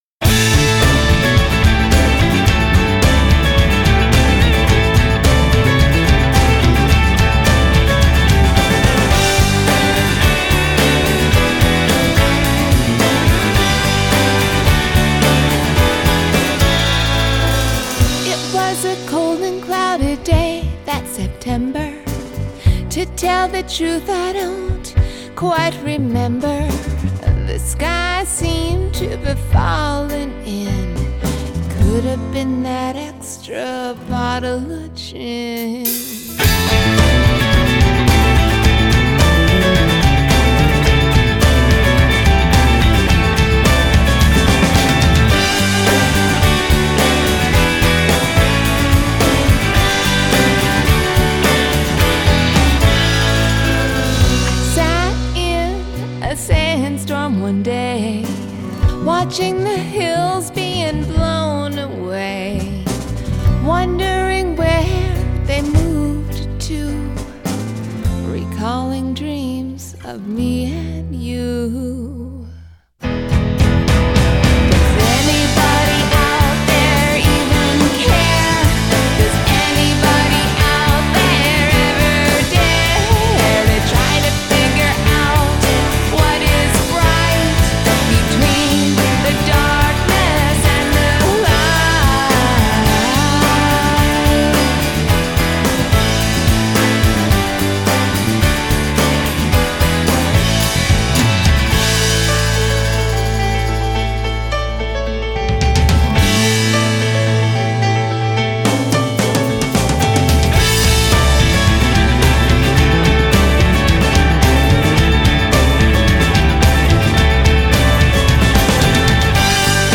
Adult Contemporary , Comedy
Indie Pop , Soft Rock